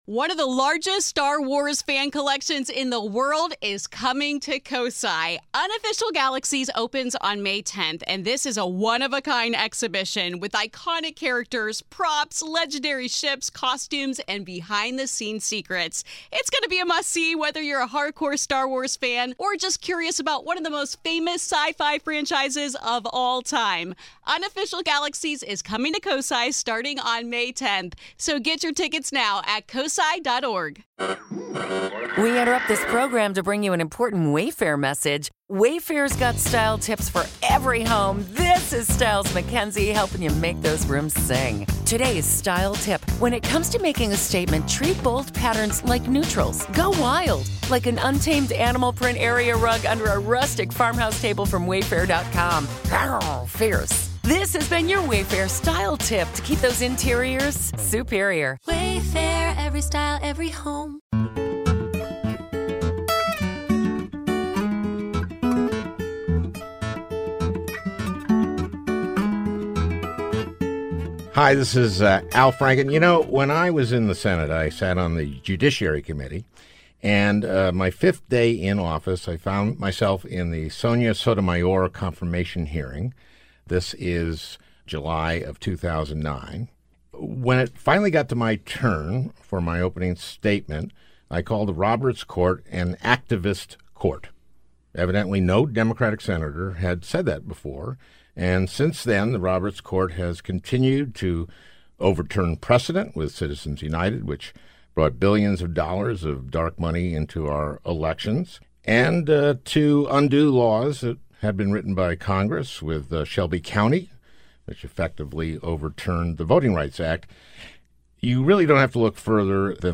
A Conversation with Jeffrey Toobin and Nancy Gertner
An in-depth, but somewhat snarky, look at the Trump administration’s success in packing the courts with right-wing, Federalist Society judges. Gertner, a Harvard Law professor, and Toobin, CNN chief legal analyst, discuss some of the most cynical, activist decisions by the Roberts Court.